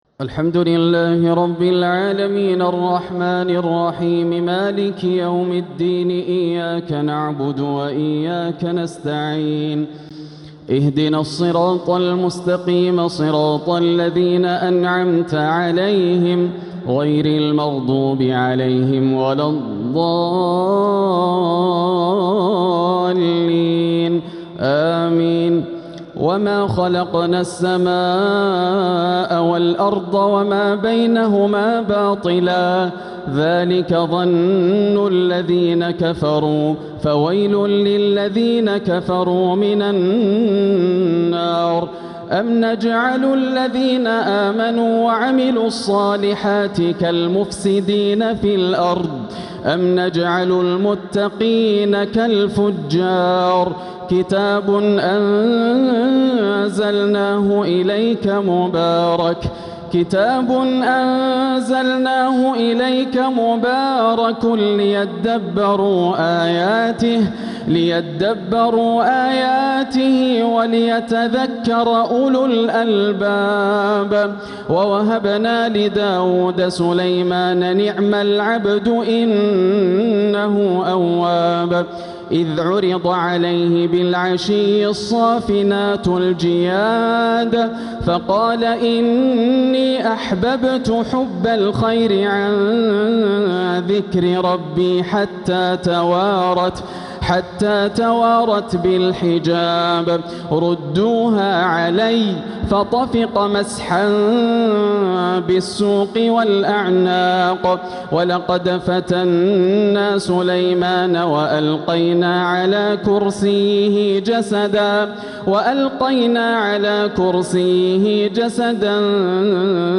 تلاوة من سورتي ص (27-88) والزمر (1-21) | تراويح ليلة 25 رمضان 1447هـ > الليالي الكاملة > رمضان 1447 هـ > التراويح - تلاوات ياسر الدوسري